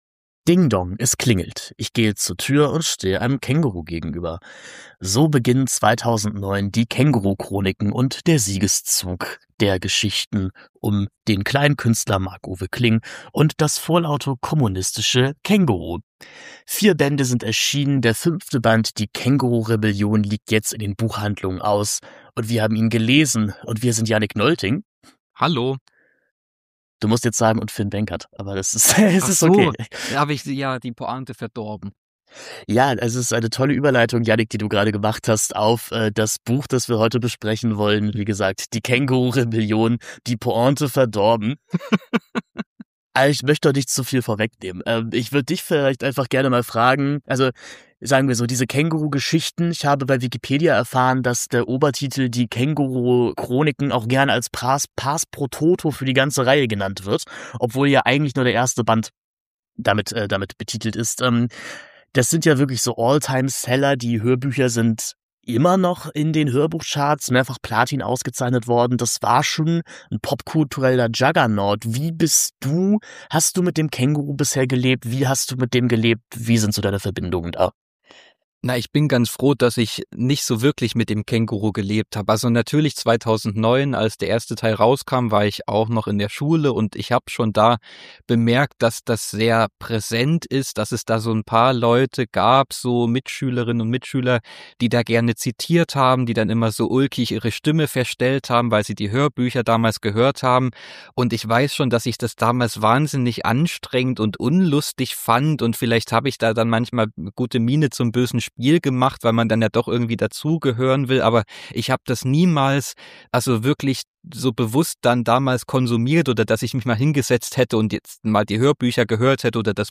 Ding Dong! Es klingelt (auch in dieser Podcastepisode).
Hört zu, wie die beiden Kritiker über Echochamber-Literatur, Zielgruppenfragen und die Relevanz des Textes an sich diskutieren.